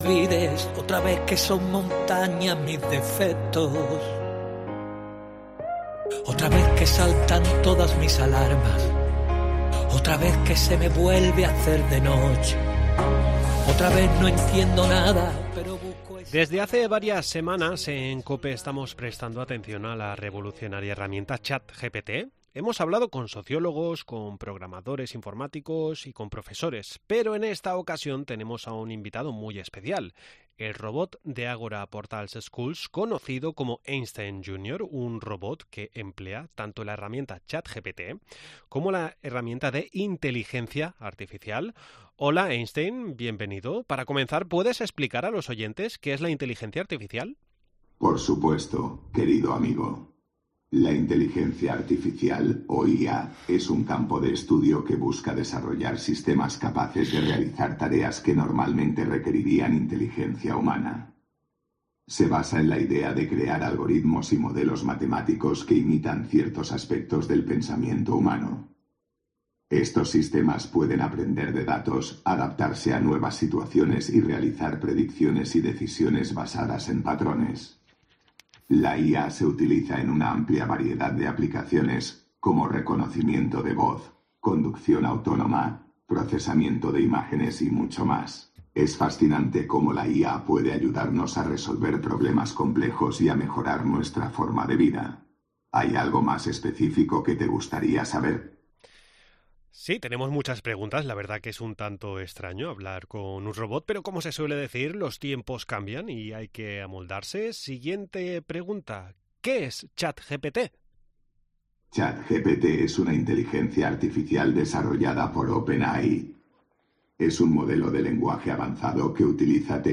AUDIO: Por primera vez hablamos con una Inteligencia Artificial.